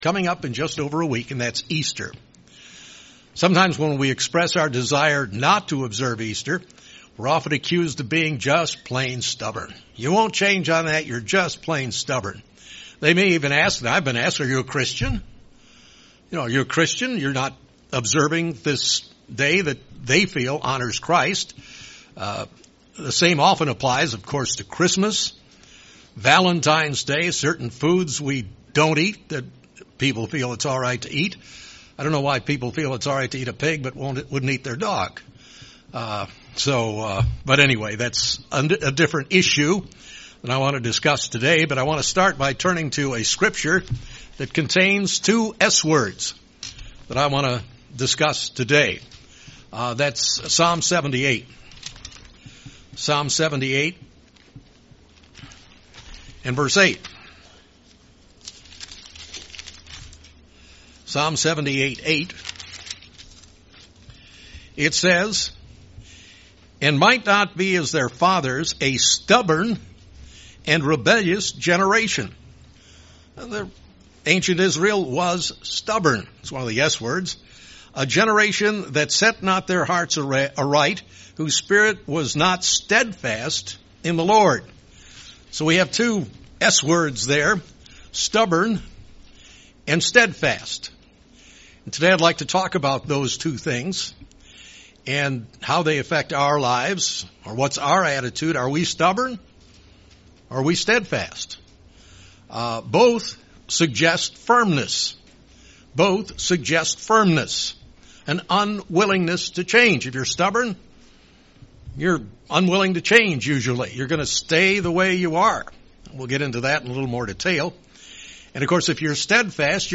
What's the difference between steadfastness and stubbornness? In this sermon, the speaker looks at the two topics of steadfastness and stubbornness.
Given in Springfield, MO